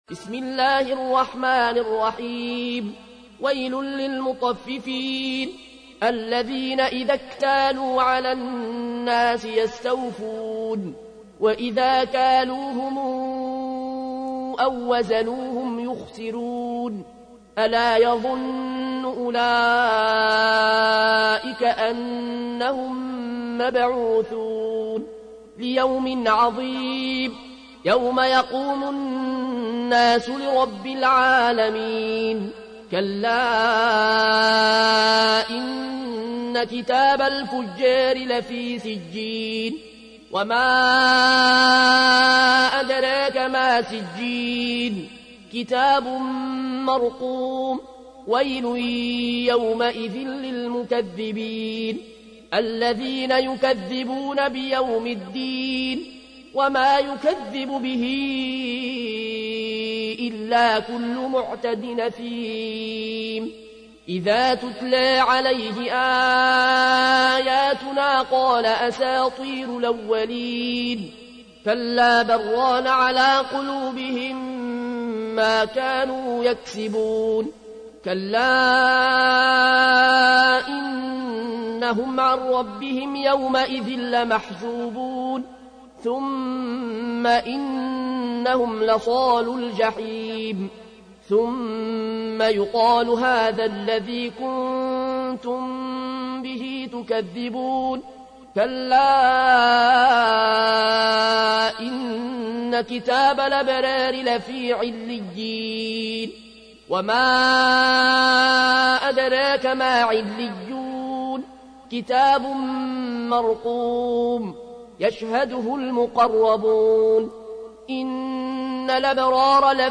تحميل : 83. سورة المطففين / القارئ العيون الكوشي / القرآن الكريم / موقع يا حسين